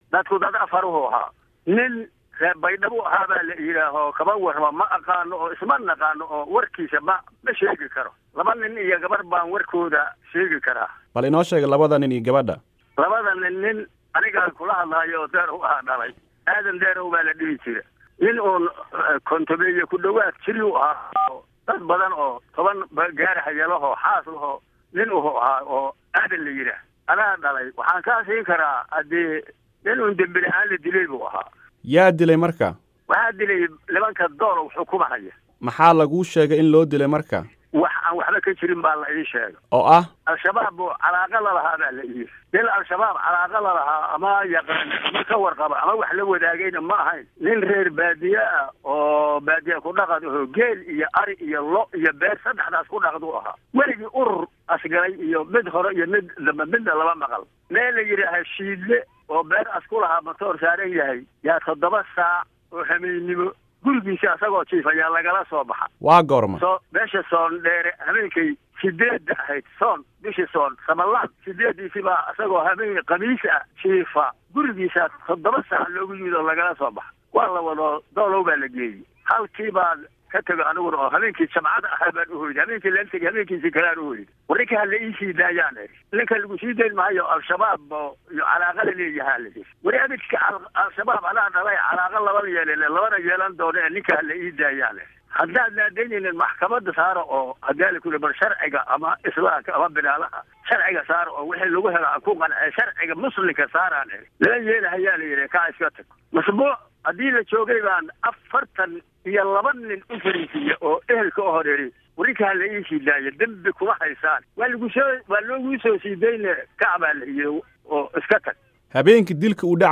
Wareysi: Dilkii Doolow